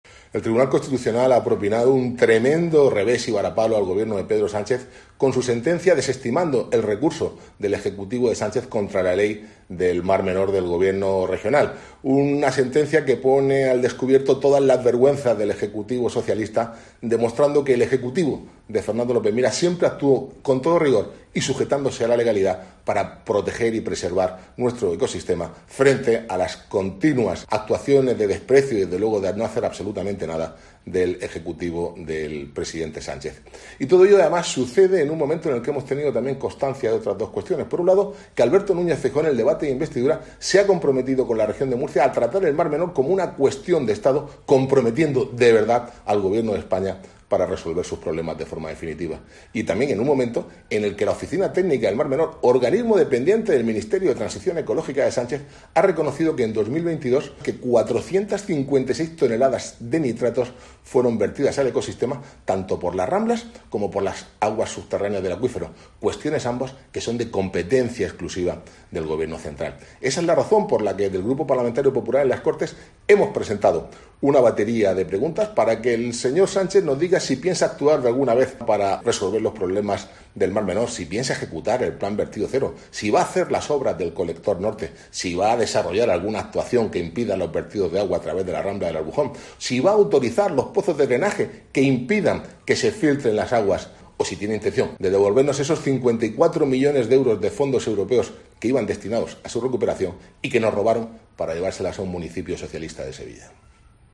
Francisco Bernabé, senador del Partido Popular por la Región de Murcia